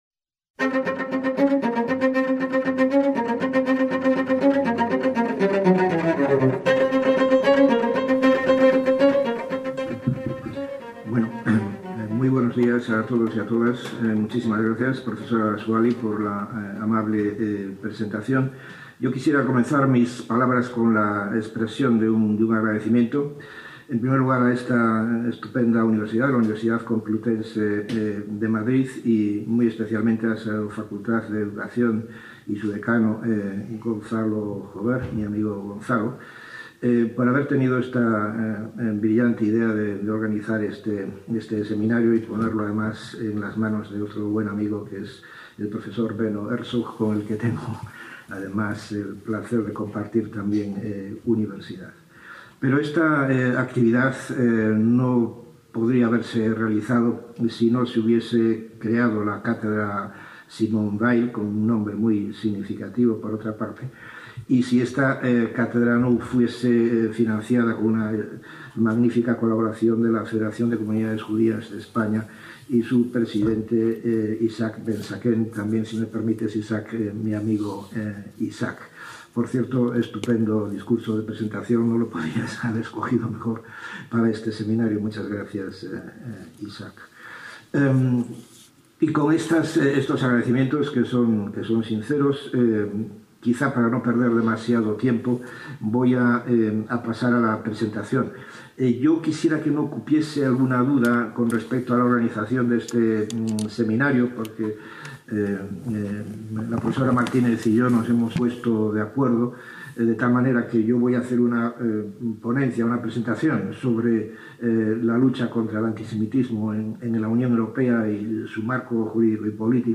VI SEMINARIO INTERNACIONAL DE ANTISEMITISMO 2022
celebrado el jueves 17 y viernes 18 de noviembre de 2022 en el Aula Magna de la Facultad de Educación de la Universidad Complutense
ponencia